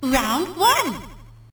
snd_boxing_round1_bc.ogg